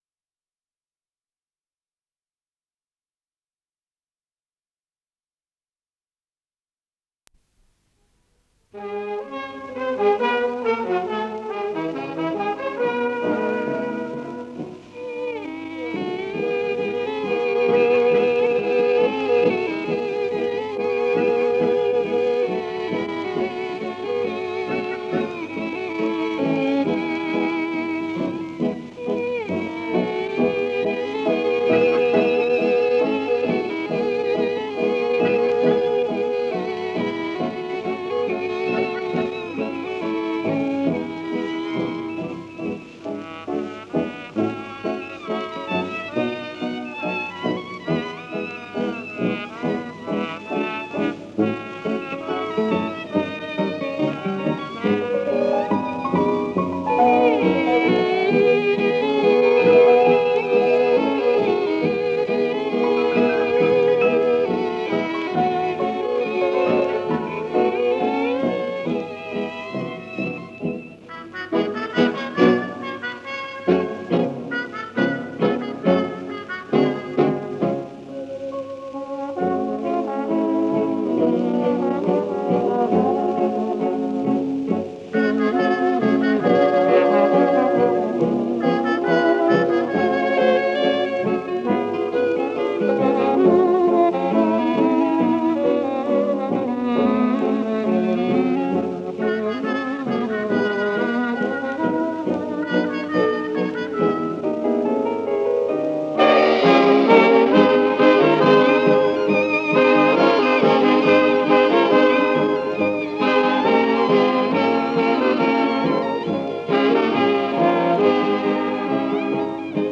с профессиональной магнитной ленты
ПодзаголовокТанго
Скорость ленты38 см/с
Тип лентыORWO Typ 106